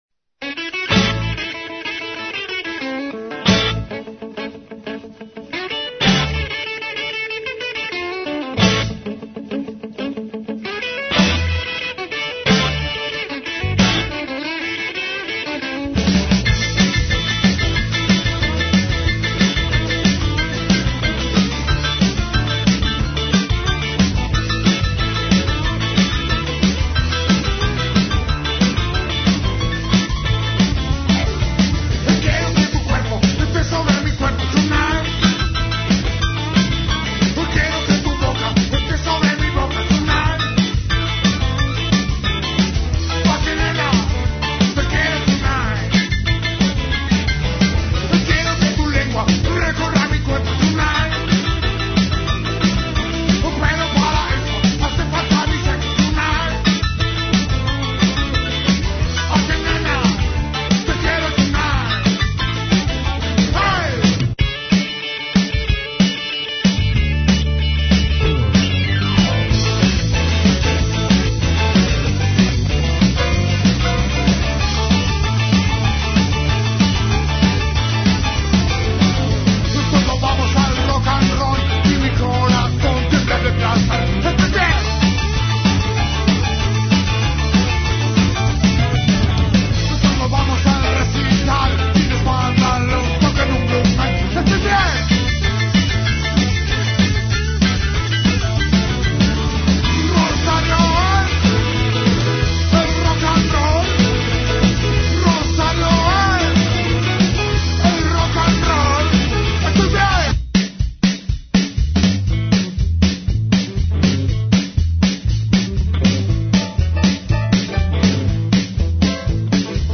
Rock'n roll & blues